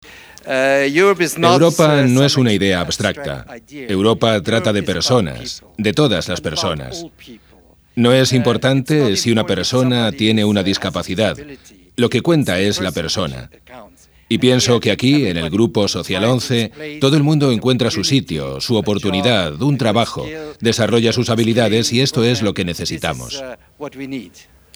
"Europa no es una idea abstracta, Europa trata de  personas, de todas las personas. Vuestro proyecto es un gran ejemplo que necesitamos", afirmó el comisario.